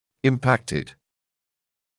[ɪm’pæktɪd][им’пэктид]ретинированный